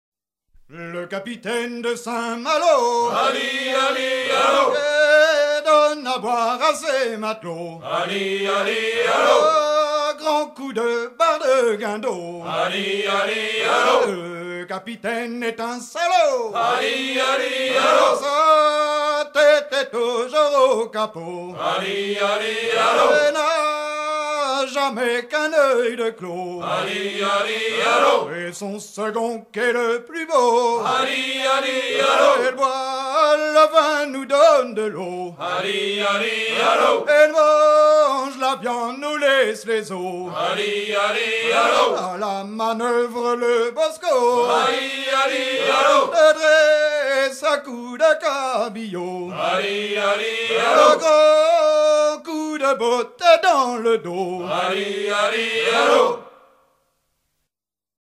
gestuel : à hisser à grands coups
circonstance : maritimes